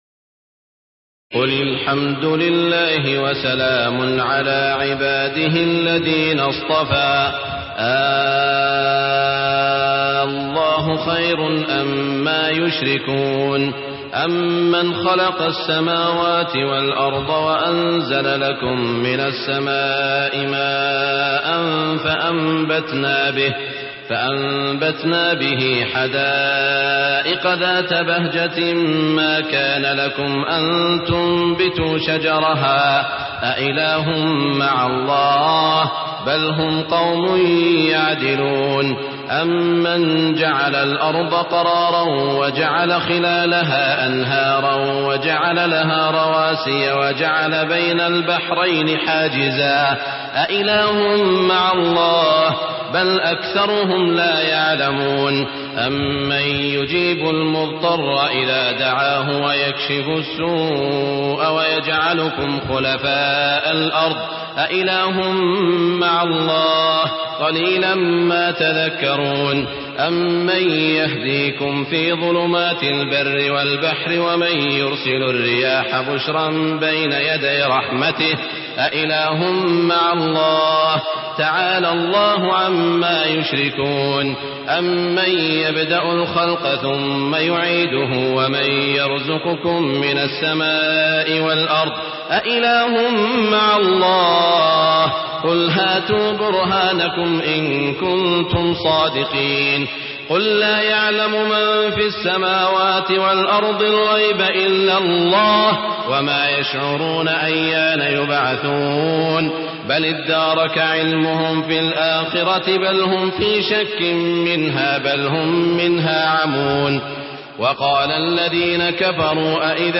تراويح الليلة التاسعة عشر رمضان 1423هـ من سورتي النمل (59-93) و القصص (1-50) Taraweeh 19 st night Ramadan 1423H from Surah An-Naml and Al-Qasas > تراويح الحرم المكي عام 1423 🕋 > التراويح - تلاوات الحرمين